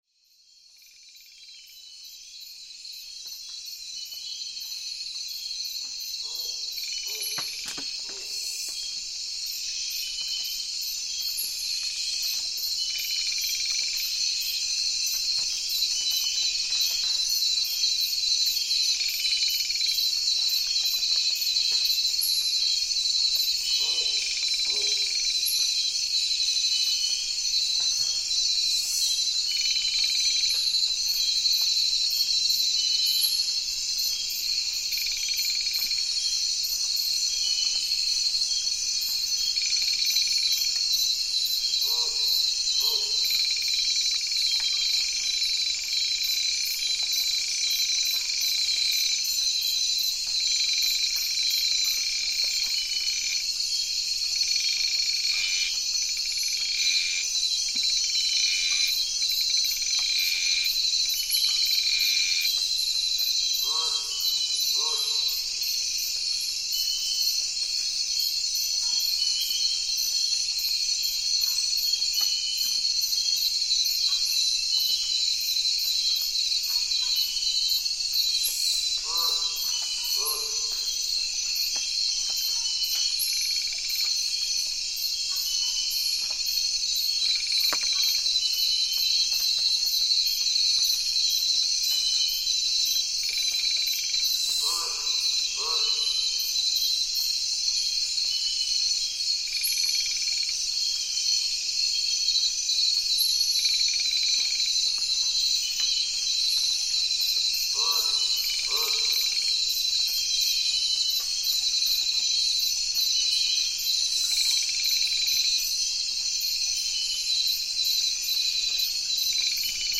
Captured deep within Kichua tribal territory in the Ecuadorian Amazon, this nocturnal soundscape reveals a hidden world that awakens after dusk. Above, bats flicker through the darkness, their wings slicing the air in delicate beats. Insects surge into a symphony of pulses and hums — sharp, electric, as though the forest itself were alive with circuitry. A solitary monkey stirs unseen branches, its movements dissolving into the whispers of leaves. From afar, a howl drifts through the trees — haunting, fragile, a fleeting brush with the unseen. Beneath it all, a stream murmurs softly, threading its rhythm through the night’s weave.
It is a portal into the rainforest’s secret hours — where every ripple, chirp, and rustle speaks of survival, connection, and fragile wonder.